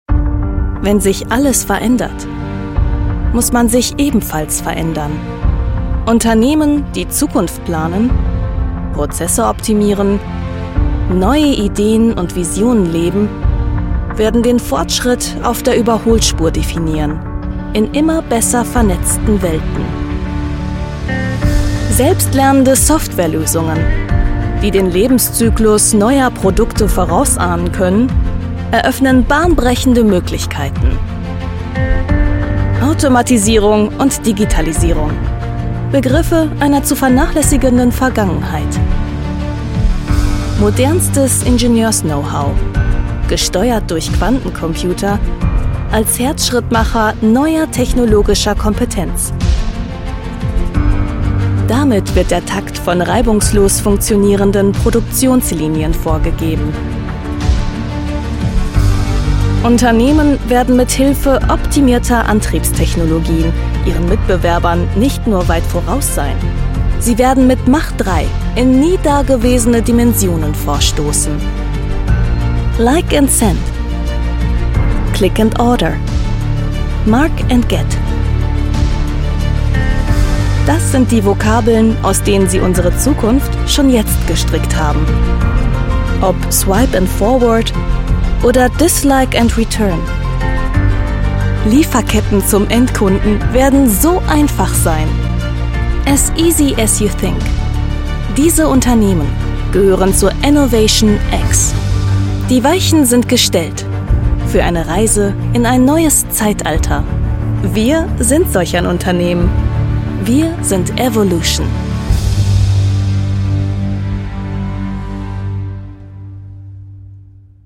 Professionelle Sprecherin mit eigenem Studio.
Kein Dialekt
Sprechprobe: Industrie (Muttersprache):